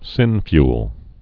(sĭnfyəl)